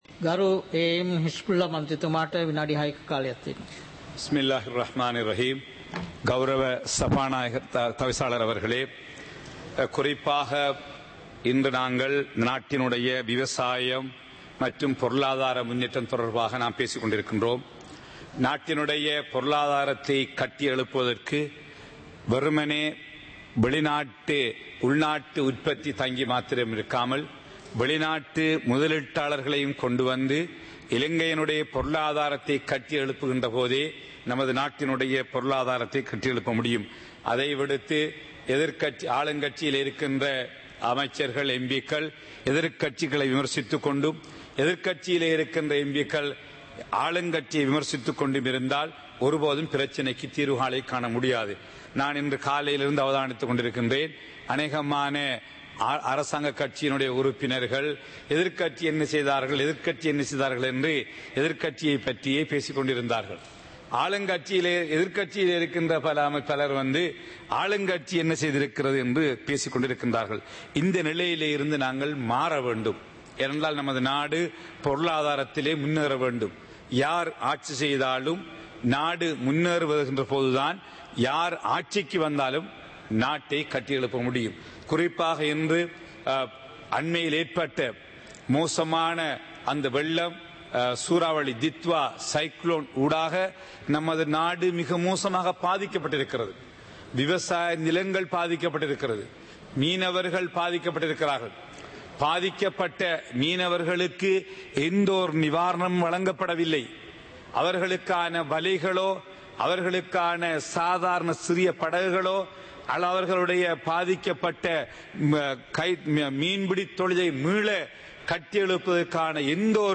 இலங்கை பாராளுமன்றம் - சபை நடவடிக்கைமுறை (2026-02-18)
நேரலை - பதிவுருத்தப்பட்ட